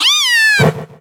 Cri de Flamiaou dans Pokémon Soleil et Lune.